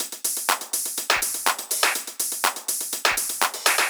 ENE Beat - Perc Mix 1.wav